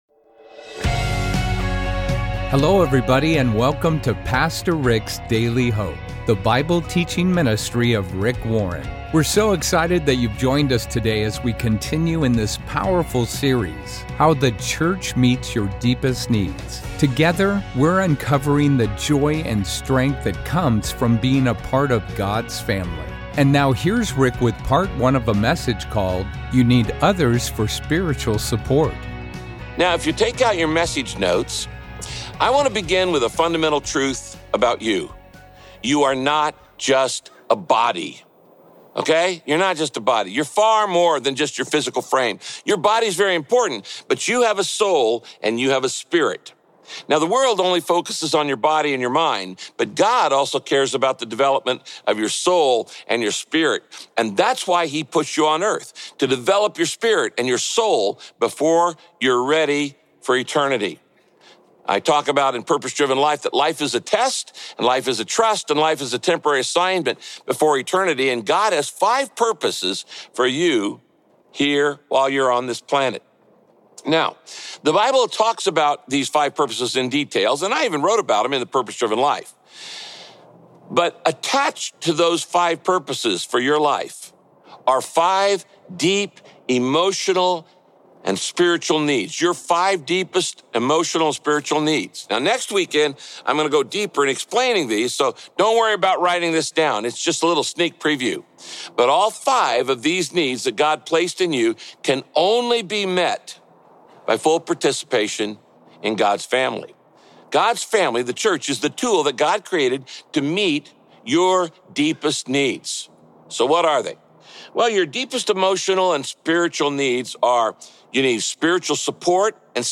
What gifts and talents have God given you? Listen to this message from Pastor Rick to learn why the church is the best place to discover and develop your spiritual gifts.